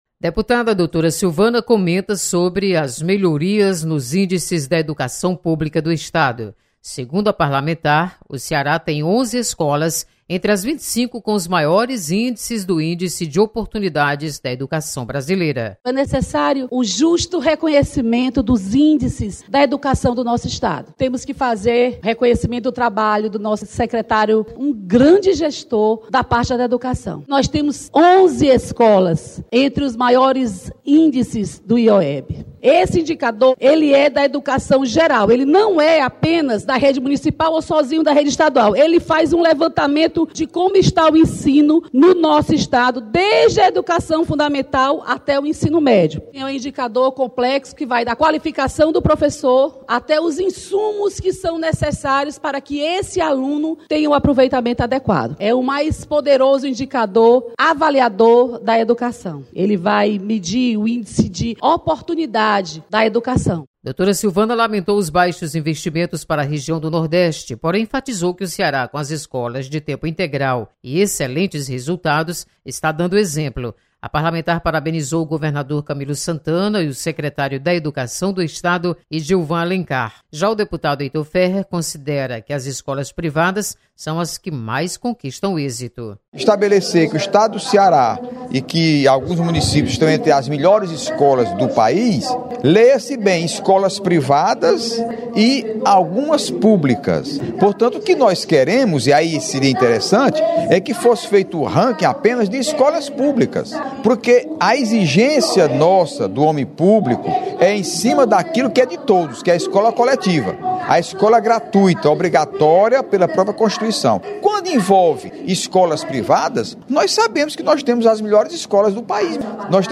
Deputados comentam sobre educação no Ceará.